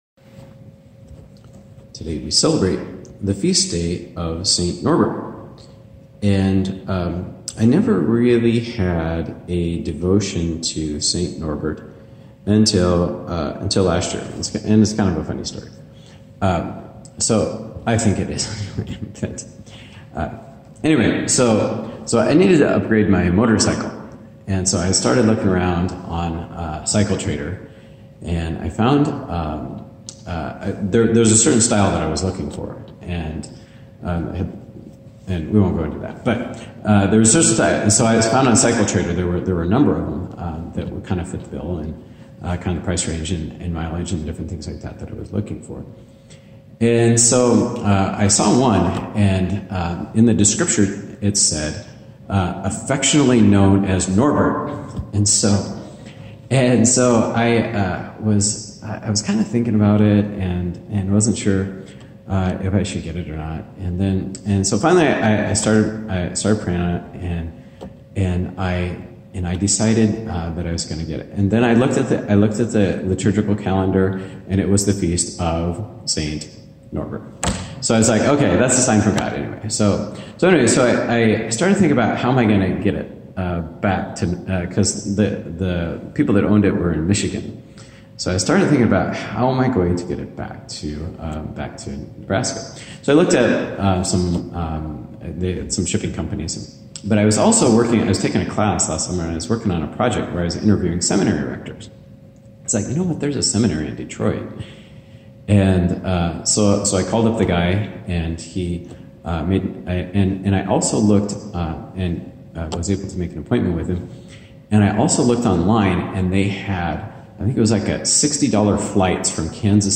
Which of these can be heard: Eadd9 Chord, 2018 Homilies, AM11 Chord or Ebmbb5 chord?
2018 Homilies